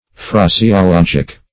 Phraseologic \Phra`se*o*log"ic\